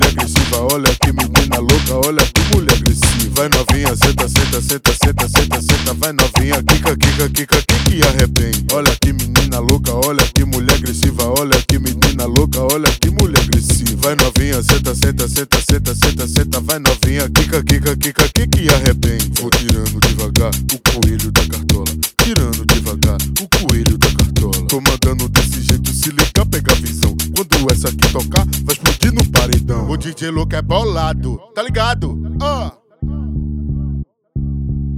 Brazilian Baile Funk
Жанр: R&B / Соул / Фанк